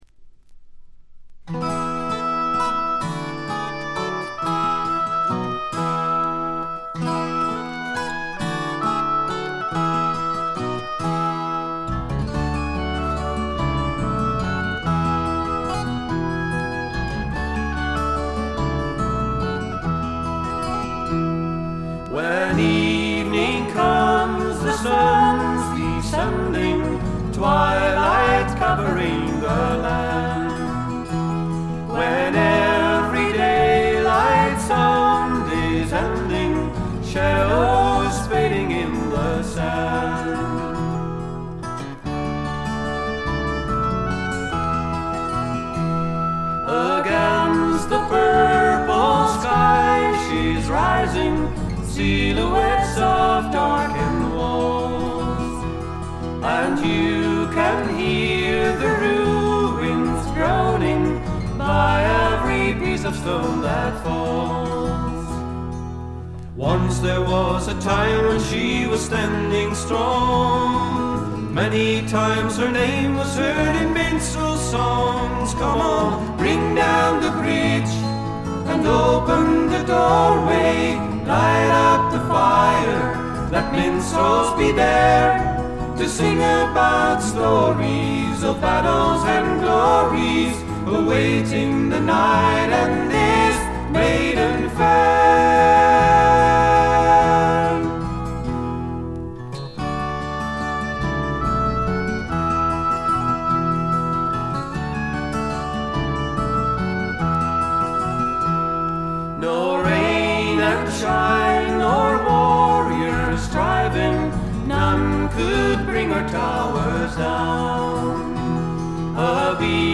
1stの木漏れ日フォーク的な穏やかさ、暖かさを残しながらも、メリハリの利いた演奏と構成で完成度がぐんと上がっています。
ジェントルな男性ヴォーカル、可憐で美しい女性ヴォーカル（ソロパートが少ないのがちょっと不満）、見事なコーラスワーク。
Acoustic Guitar, Vocals
Accordion
Bass Guitar
Flute
Oboe
Violin